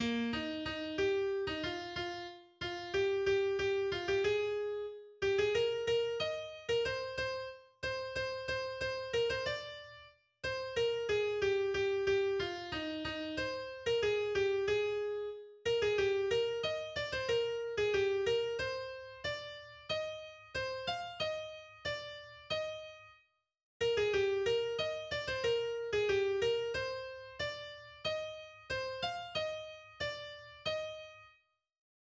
Das Trinklied